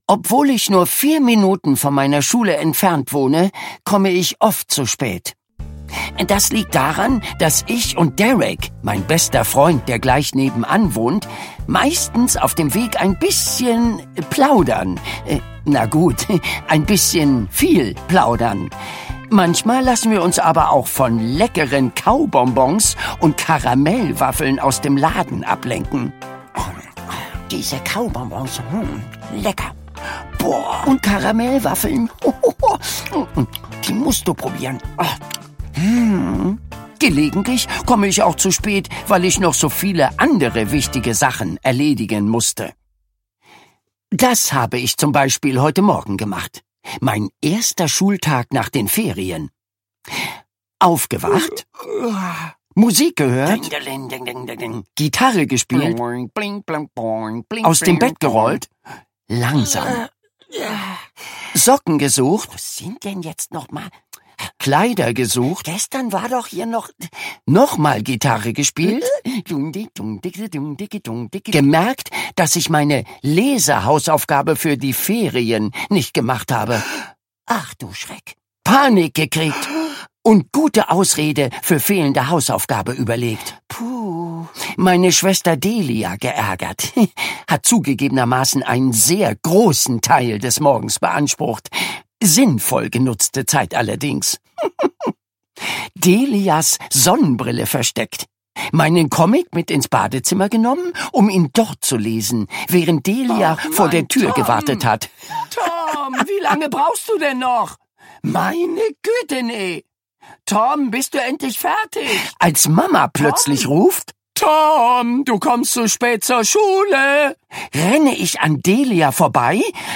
Hörbuch: Tom Gates 1.